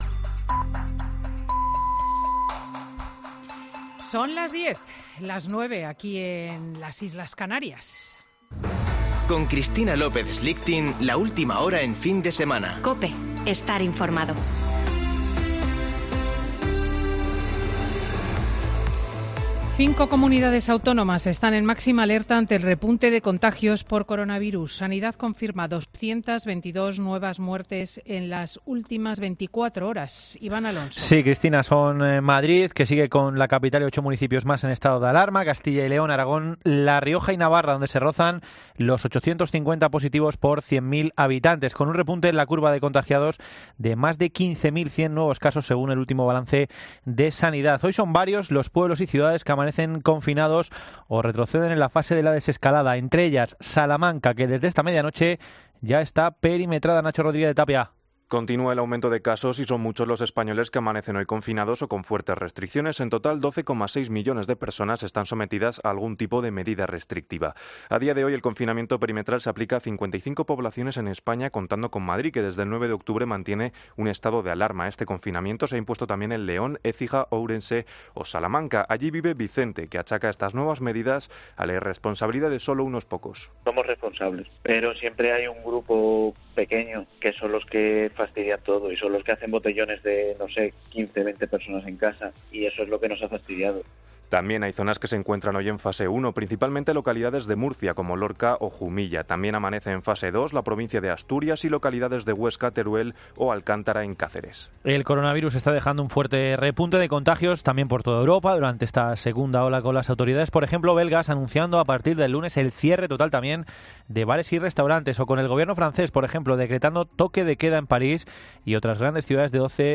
Boletín de noticias de COPE del 17 de Octubre de 2020 a las 10.00 horas